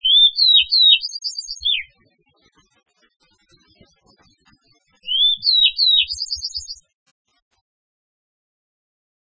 2124g「鳥の鳴声」
〔クロジ〕ピーフィーフィーチョチョピー（さえずり）／針葉樹林などで繁殖，少ない